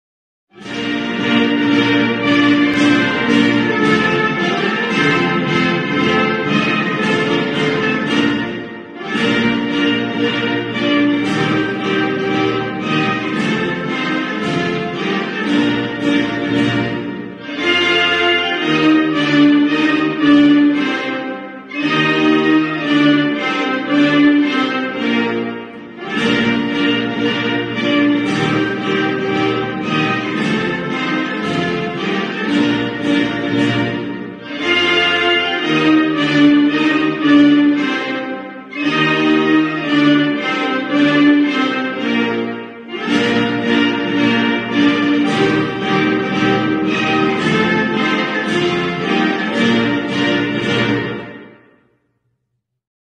凱撒溫絲頓皇室頌歌-凱撒之鷹進行曲.mp3